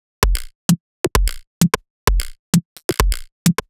Alphatown3 130bpm.wav